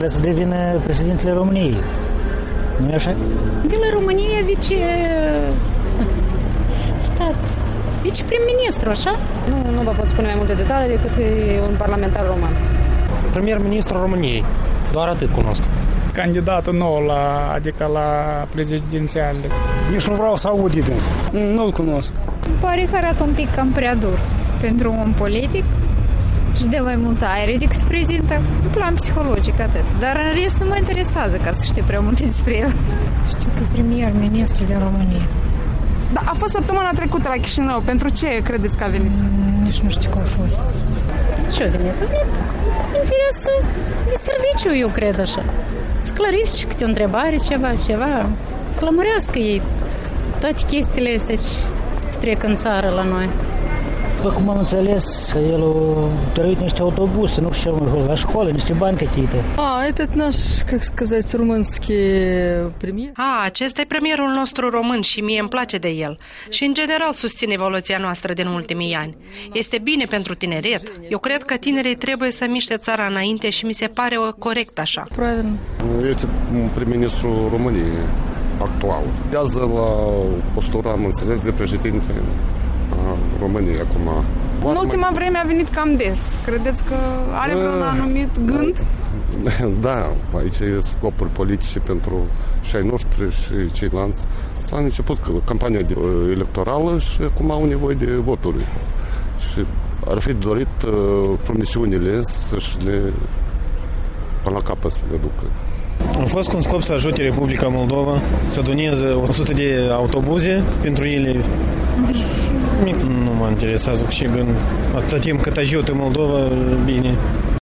Vox populi: Ce ştiu moldovenii despre Victor Ponta?